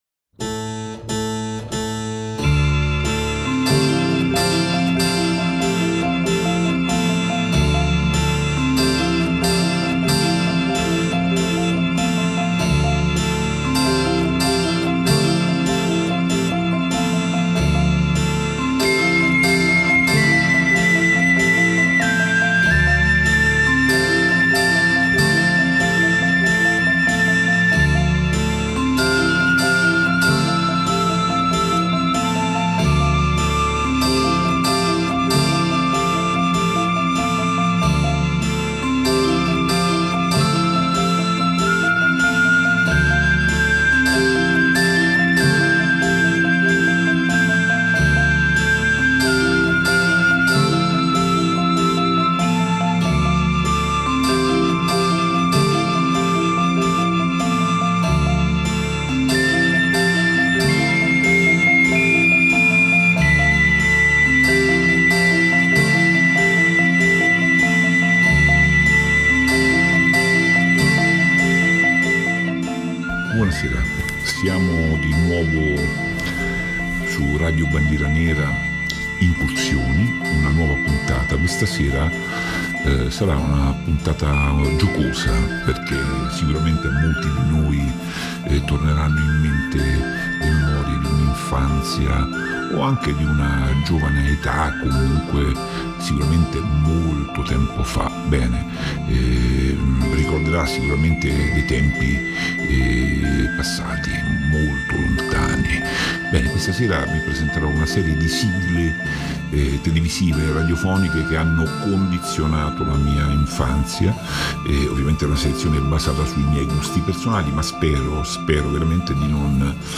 Tema della puntata: una selezione di sigle che hanno fatto la storia della televisione e della radio. Nella rubrica “Consigli Fuorvianti” ortaggi e amore per la terra, significa amore per la tradizione.